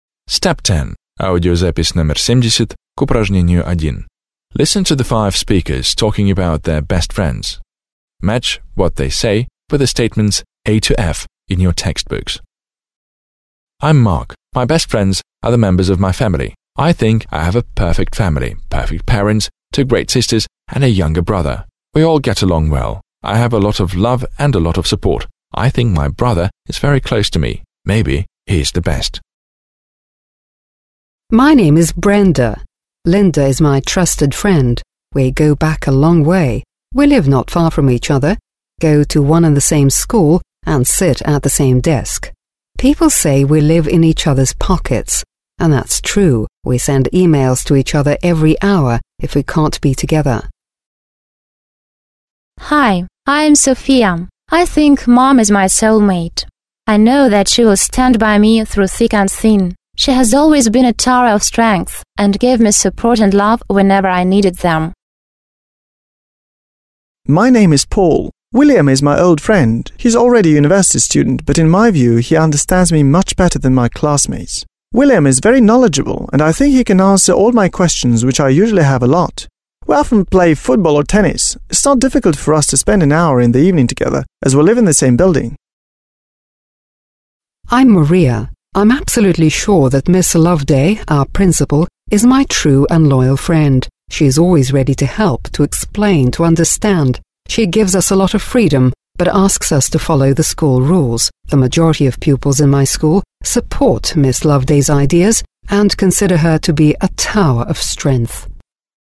1. Вы услышите, как пять человек рассказывают о своих лучших друзьях.